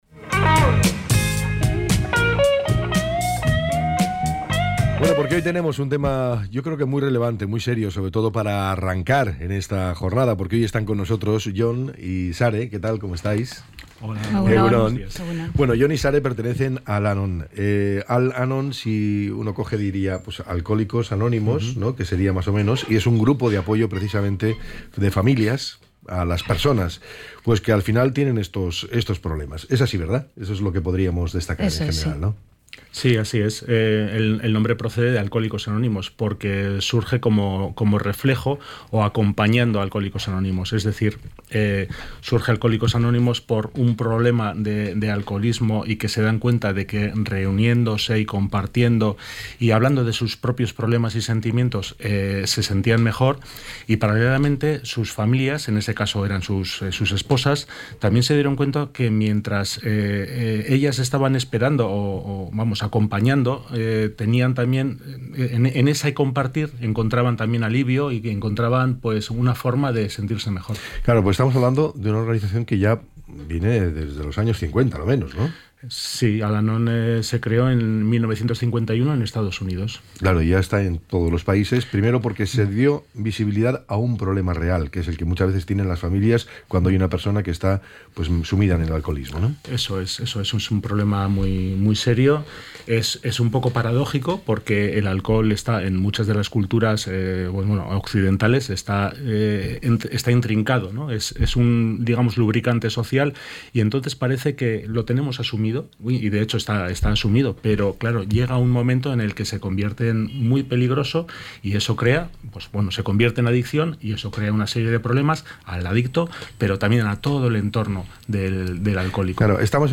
ENTREV.-AL-ANON.mp3